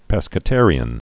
(pĕskə-târē-ən)